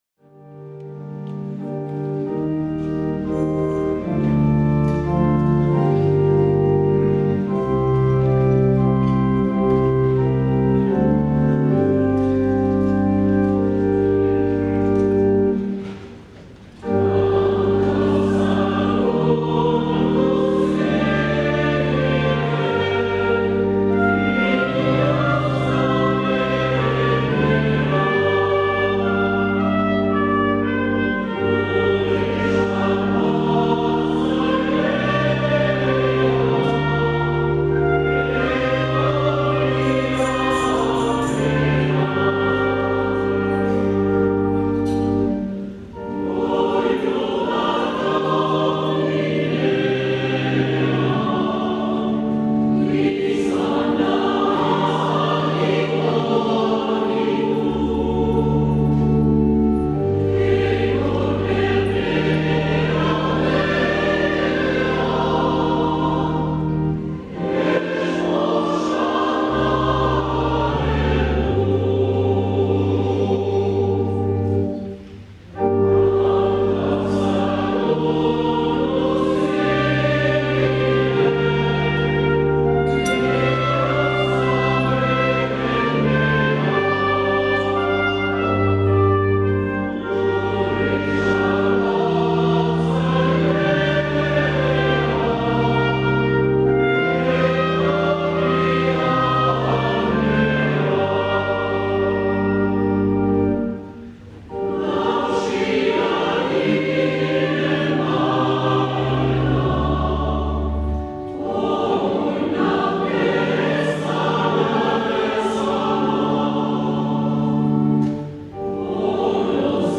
2020-11-29 Abenduko 1. Igandea B - Uztaritze